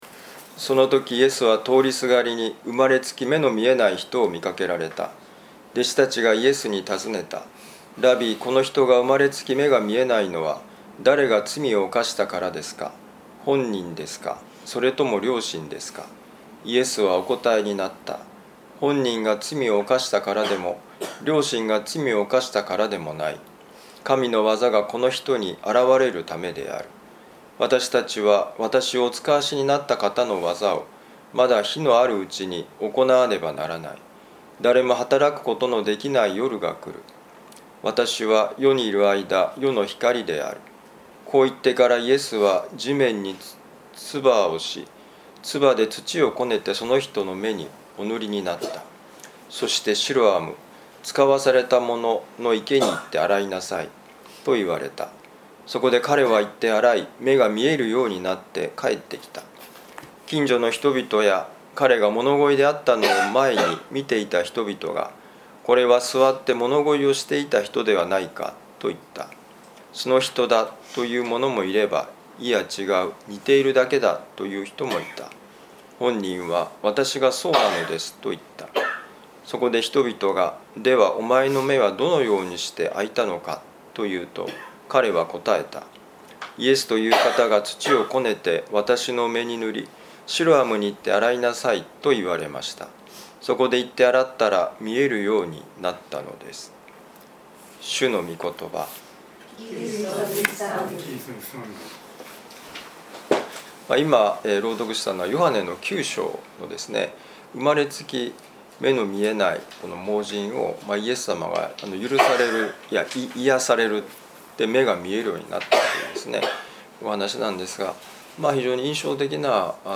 【ミサ説教】
ヨハネ福音書9章1-15節「いやしのプロセスは未来志向」2025年2月24日年間いやしのミサ旅路の里